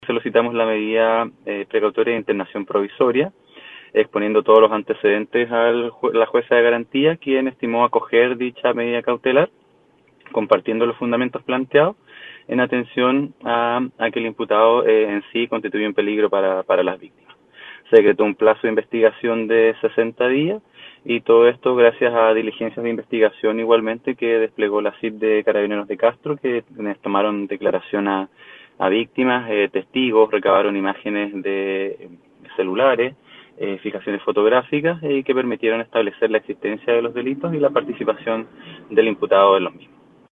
Dijo el persecutor que con las pruebas reunidas, se logró confirmar la participación del joven en los hechos acontecidos en ese sector de la ciudad de Castro.
04-FISCAL-LUIS-BARRIA-2.mp3